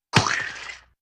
splat9.ogg